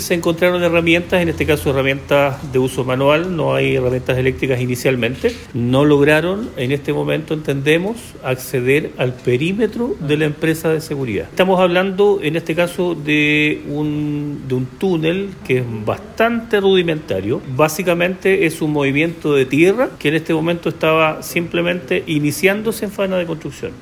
Ante ello, el fiscal Mario Elgueta, se refirió al hallazgo de este túnel de más de 2 metros de longitud que estaba en desarrollo en el ex motel El Conquistador, donde señaló que no lograron ingresar al perímetro de la empresa.
cuna-fiscal-tunel.mp3